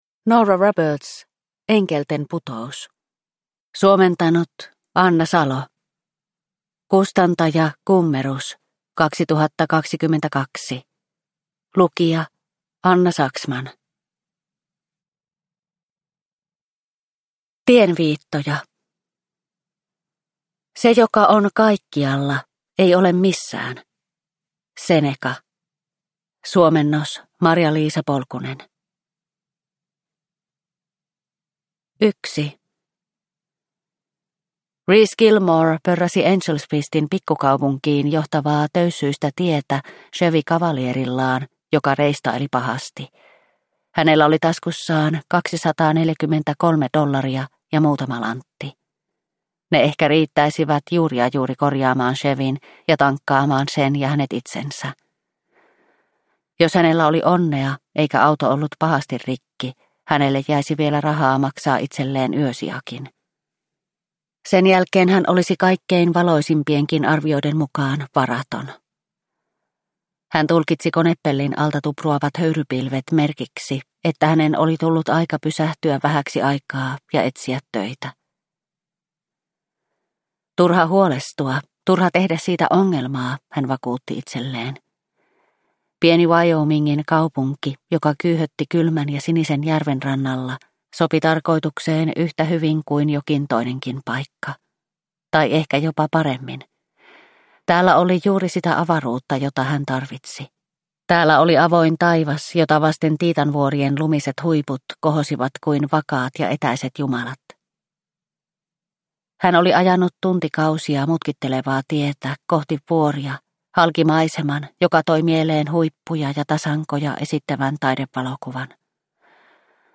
Enkelten putous – Ljudbok – Laddas ner